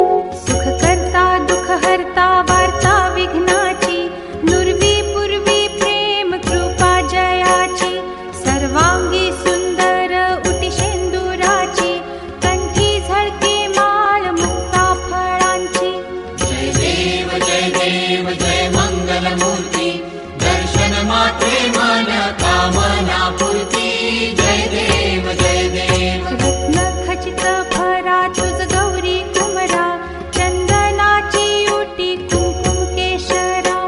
devotional tune